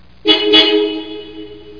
horn.mp3